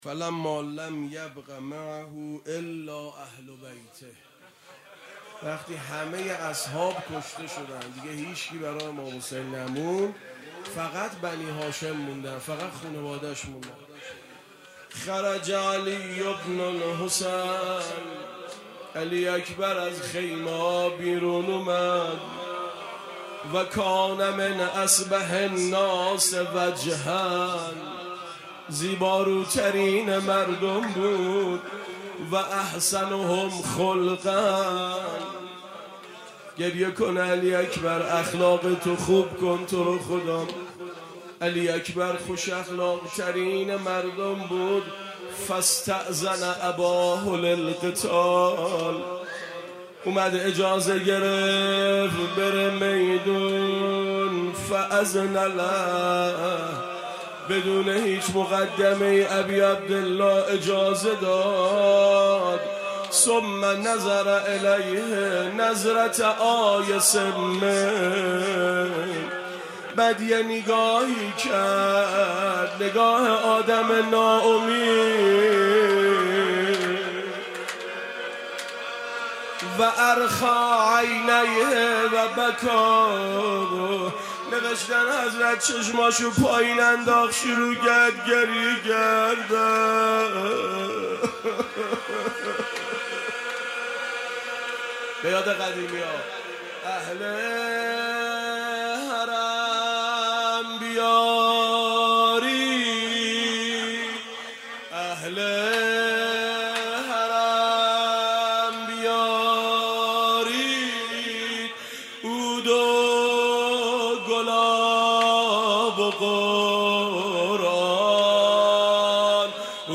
مقتل خوانی